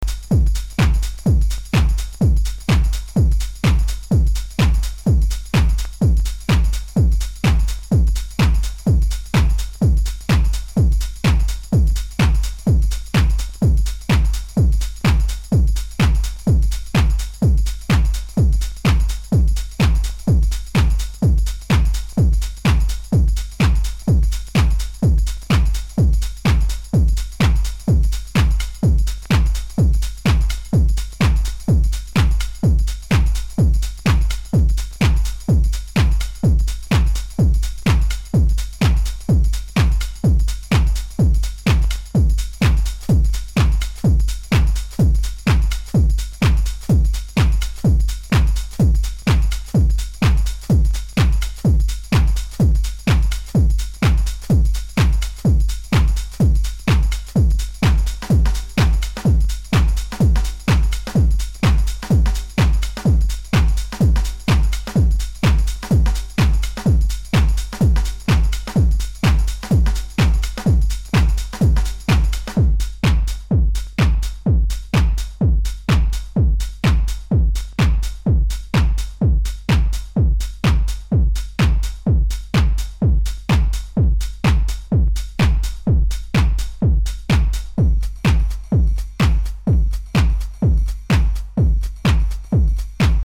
Vocal house music at its best.